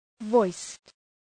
Προφορά
{vɔıst}